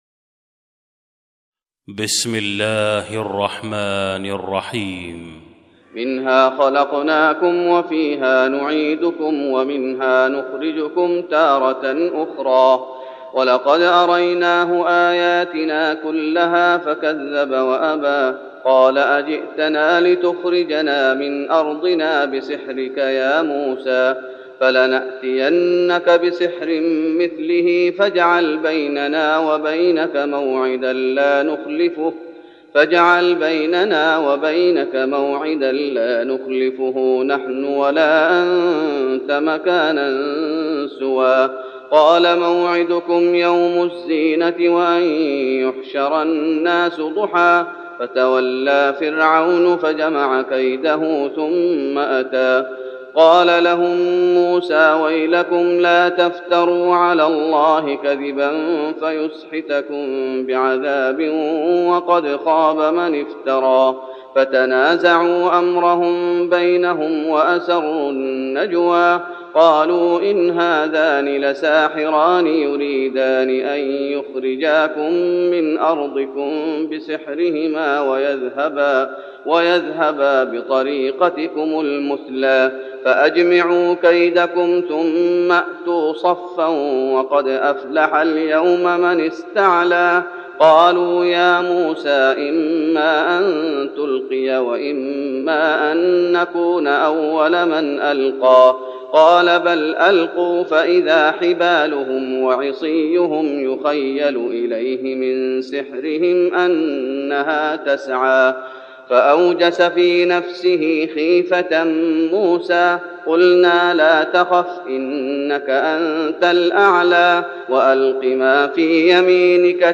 تهجد رمضان 1412هـ من سورة طه (55-135) Tahajjud Ramadan 1412H from Surah Taha > تراويح الشيخ محمد أيوب بالنبوي 1412 🕌 > التراويح - تلاوات الحرمين